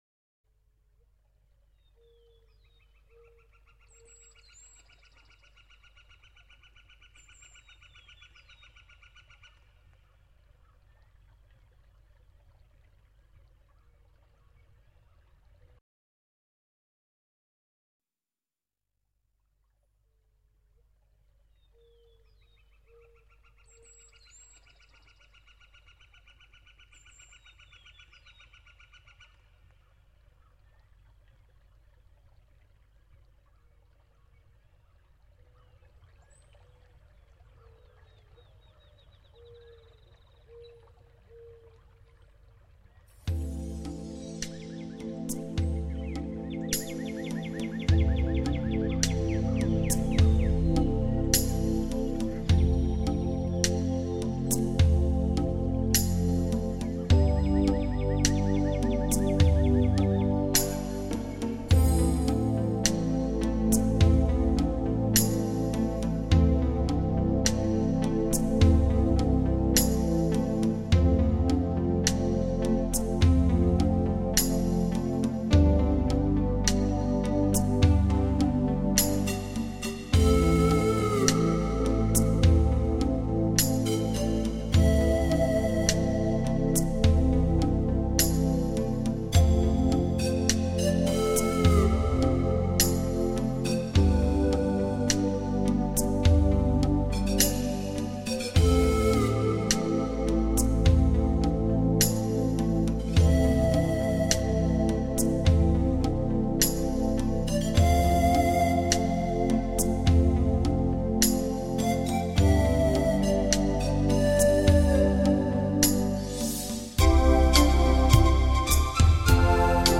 全碟大量的使用了直笛和排萧的吹奏，键盘及敲击乐器等传统南美乐器，加上隐隐的和声，揉出一派纯真的南美风格。